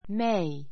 May 小 A1 méi メ イ 名詞 5月 ⦣ 詳 くわ しい使い方は June in May in May 5月に on May 5 on May 5 （読み方: （the） fifth） 5月5日に They moved to Osaka last May.